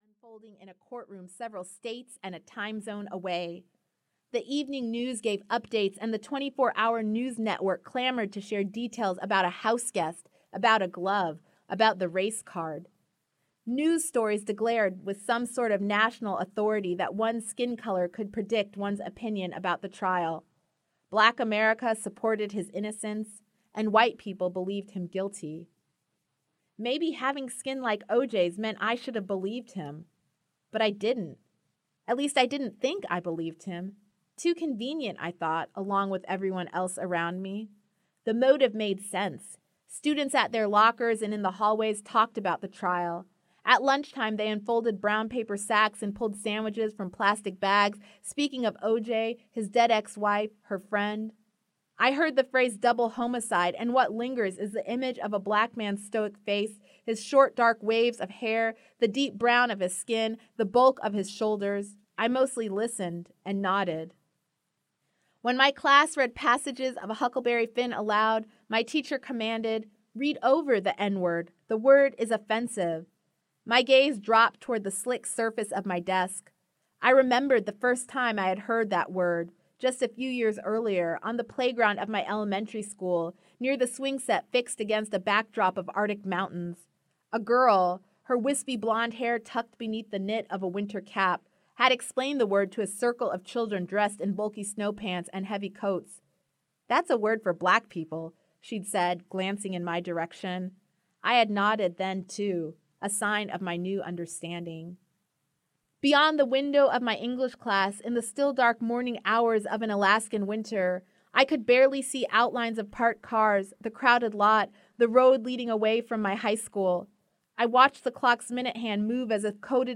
All the Colors We Will See Audiobook
Narrator
6.33 Hrs. – Unabridged